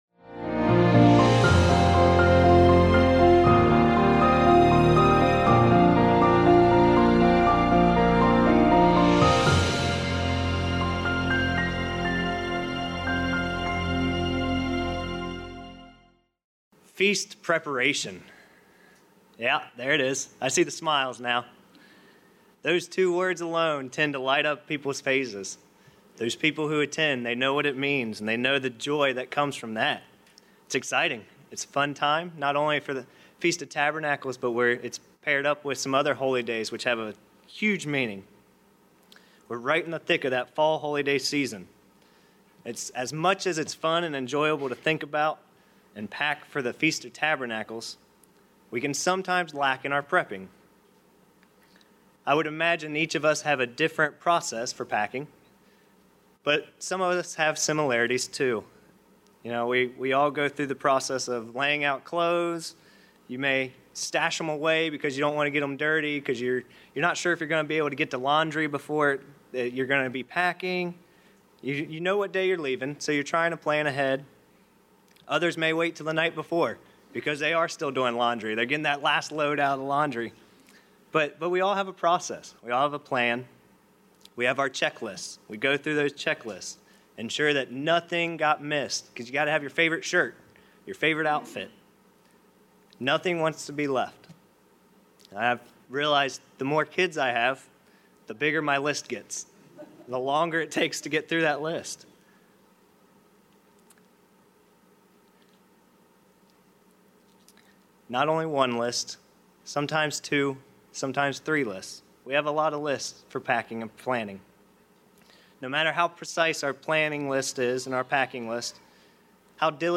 This message examines three items we should put in our spiritual suitcase as we pack for the Feast of Tabernacles.